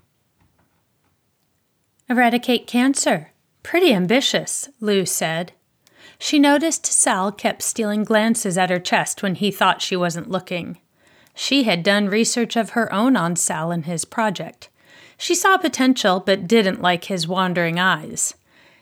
…but otherwise clean. It passes ACX Check just fine but has very serious Essing.